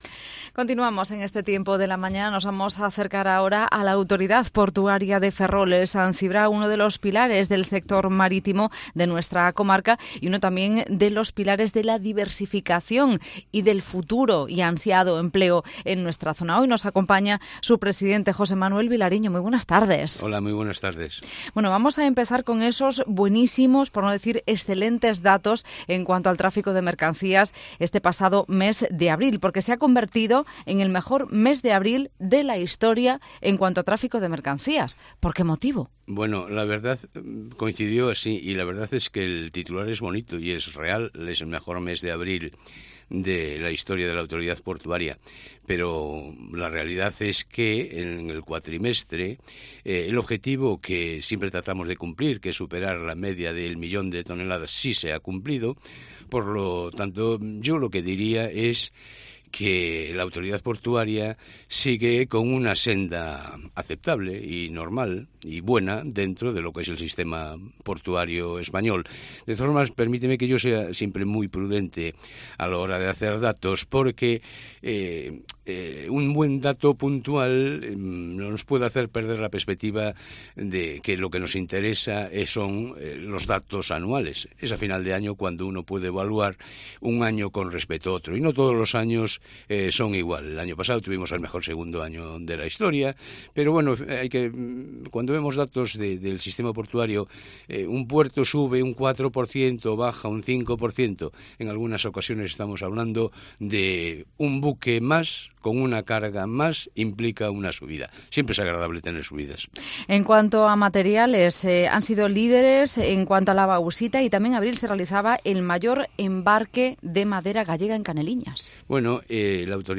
Entrevista a José Manuel Vilariño, presidente de la Autoridad Portuaria de Ferrol- San Cibrao, en Cope Ferrol